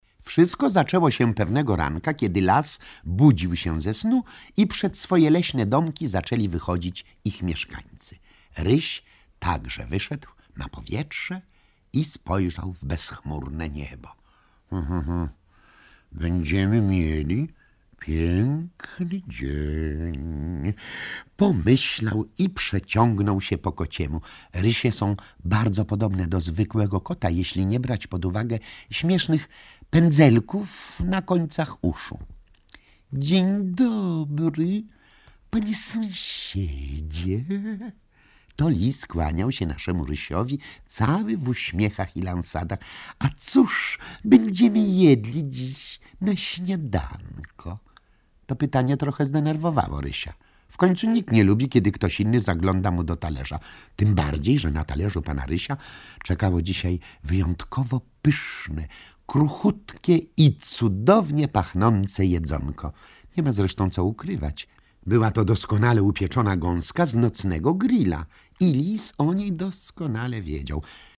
O Rysiu, lisie i wilku opowiada Mieczysław Gajda, niezapomniany smerf Maruda.